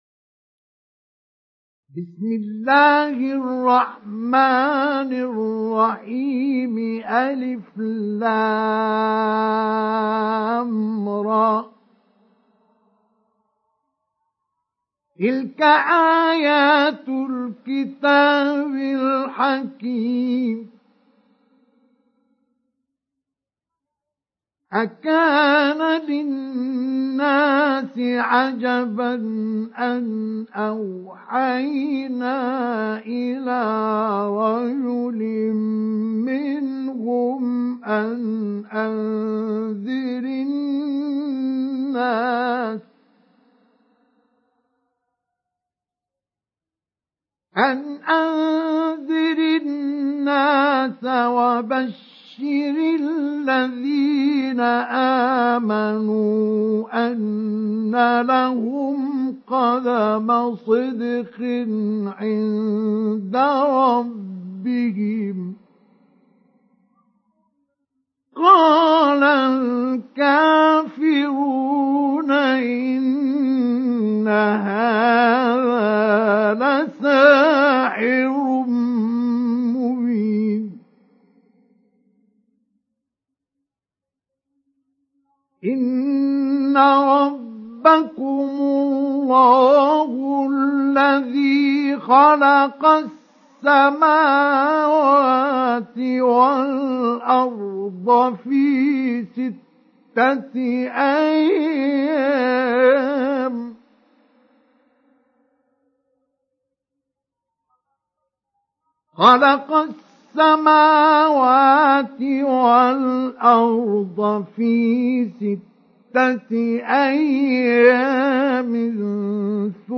استمع أو حمل سُورَةُ يُونُسَ بصوت الشيخ مصطفى اسماعيل بجودة عالية MP3.
سُورَةُ يُونُسَ بصوت الشيخ مصطفى اسماعيل